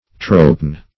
Search Result for " tropeine" : The Collaborative International Dictionary of English v.0.48: Tropeine \Tro*pe"ine\, n. (Chem.)